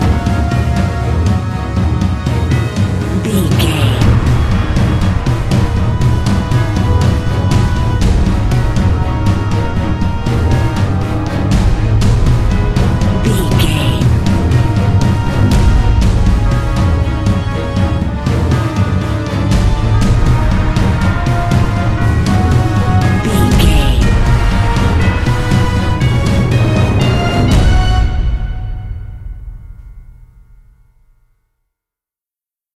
Epic / Action
Aeolian/Minor
E♭
percussion
cello
violin
orchestra